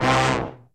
C2 POP FALL.wav